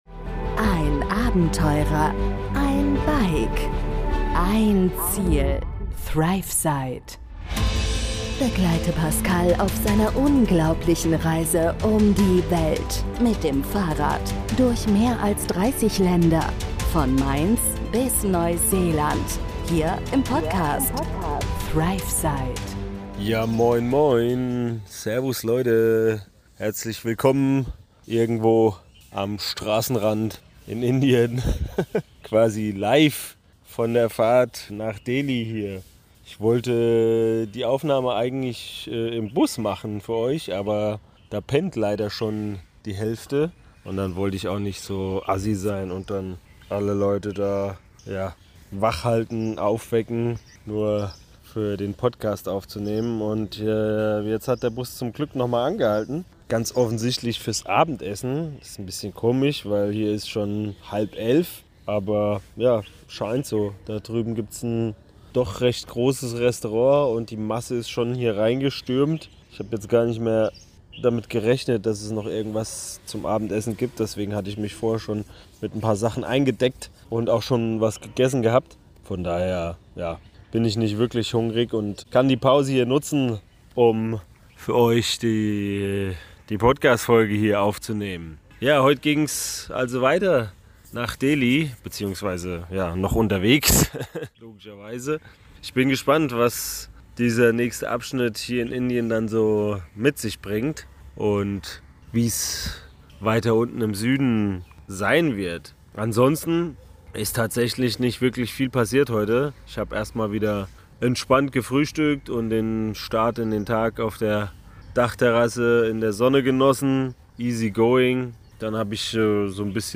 Heute melde ich mich quasi live aus Indien – irgendwo am
Straßenrand auf dem Weg nach Delhi.